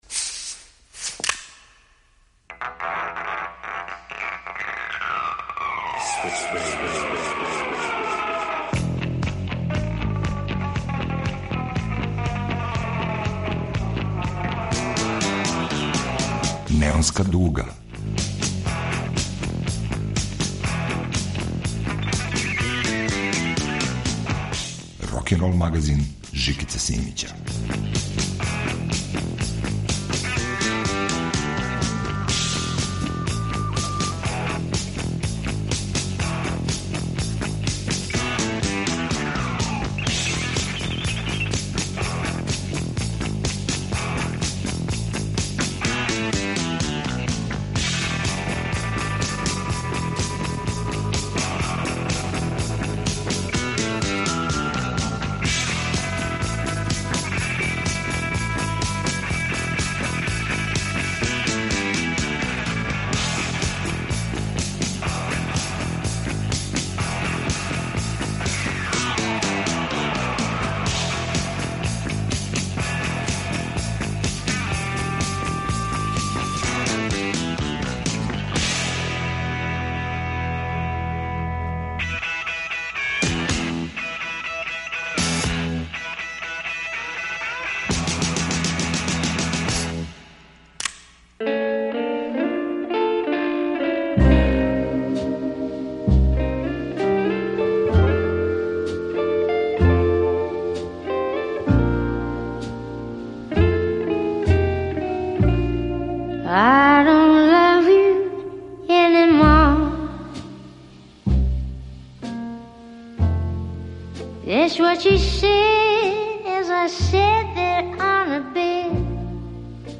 Eklektični bluz u novoj Neonskoj dugi.